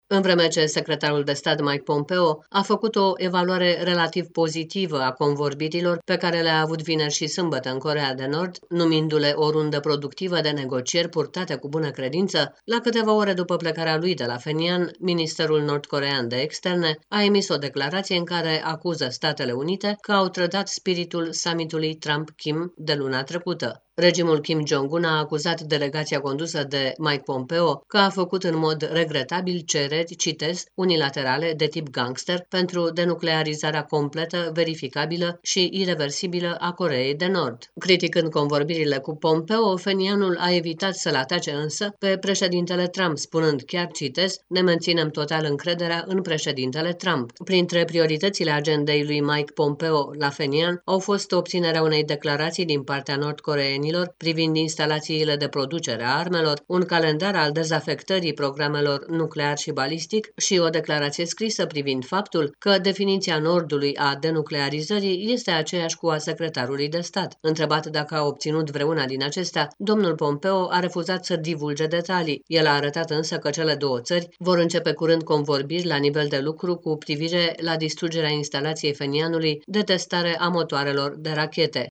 transmite din Washington